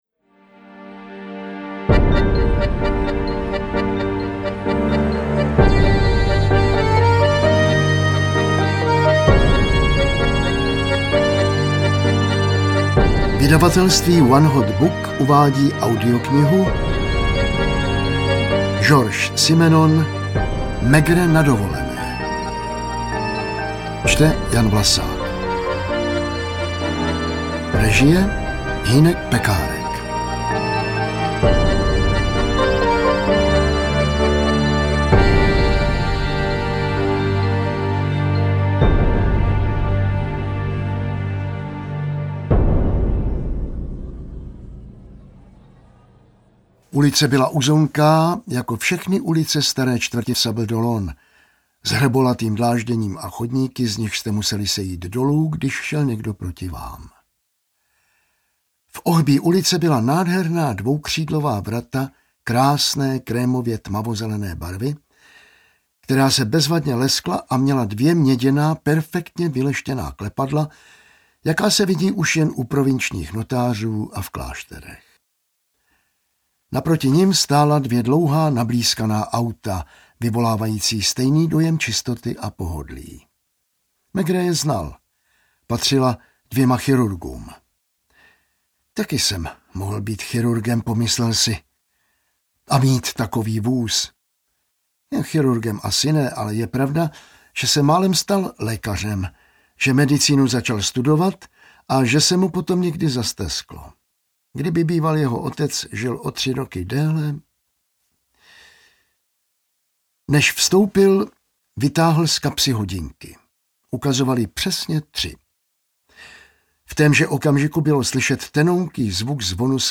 Interpret:  Jan Vlasák